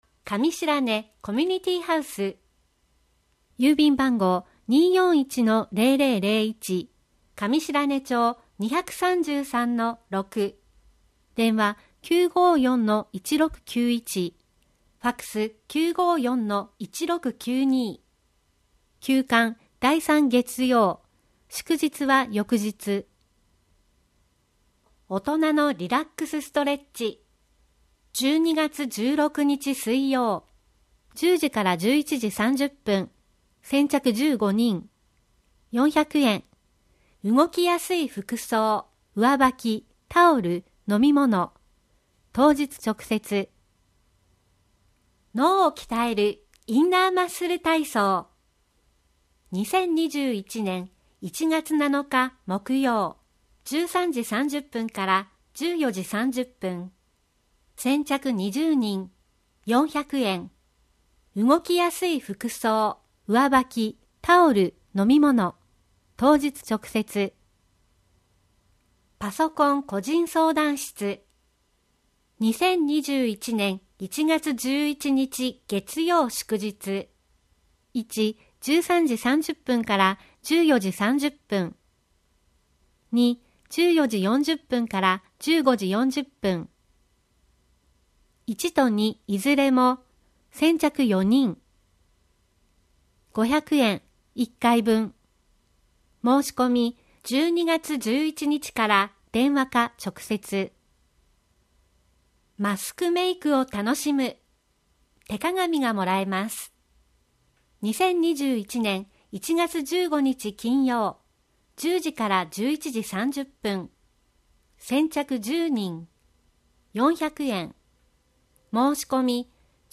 음성판